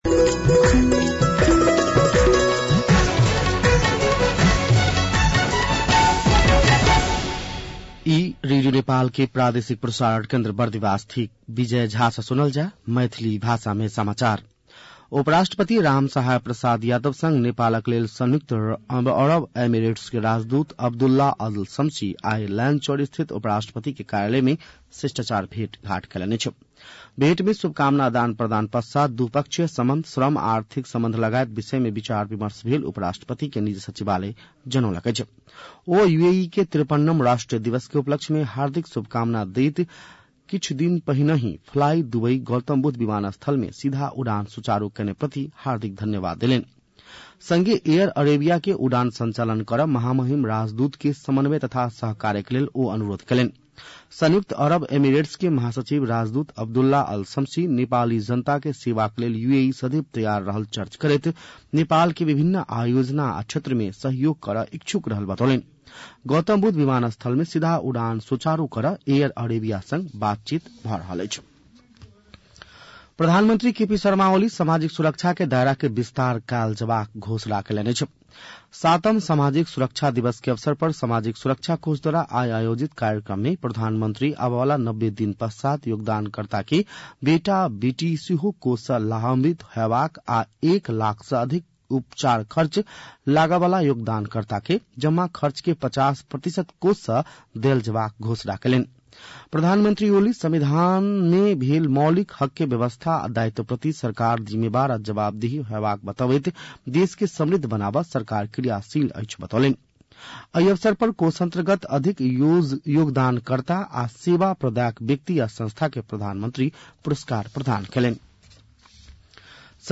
मैथिली भाषामा समाचार : १२ मंसिर , २०८१
Maithali-News-.mp3